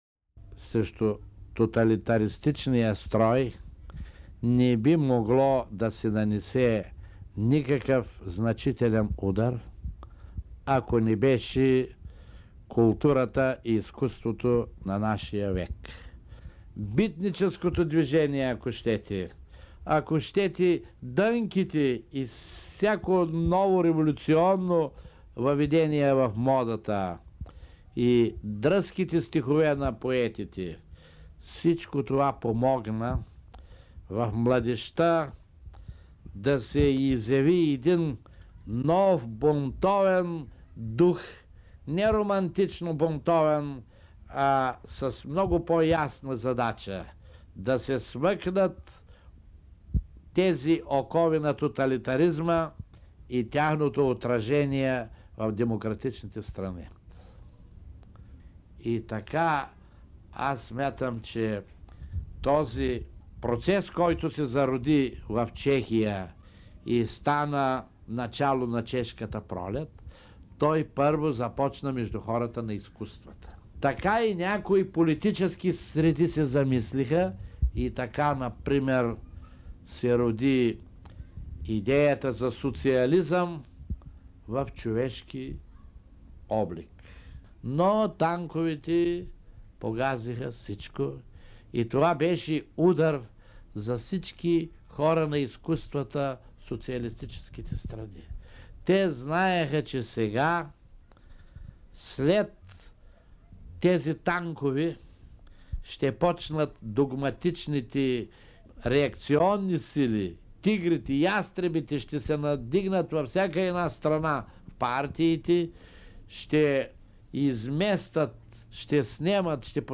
В интервю за Българското национално радио (БНР) през 1991 година писателят Радой Ралин обобщава: „Танковете опровергаха възможността да съществува социализъм с човешко лице.“